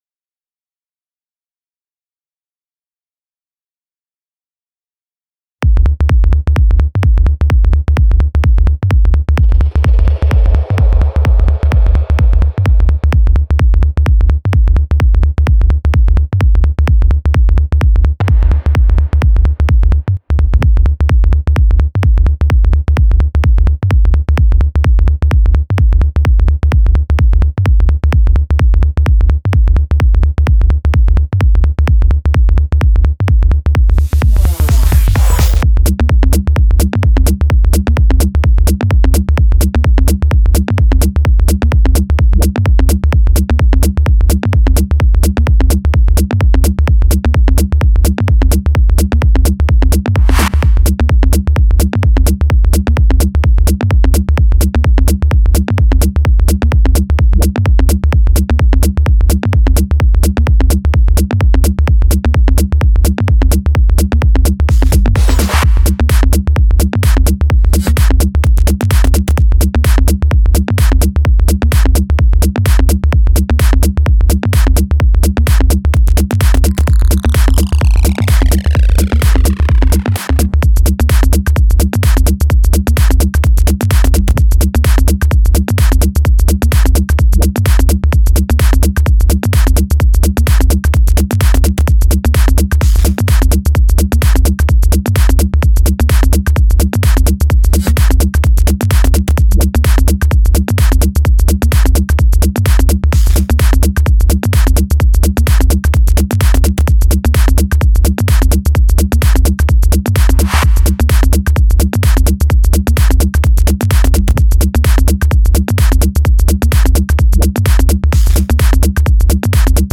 Жанр:Techno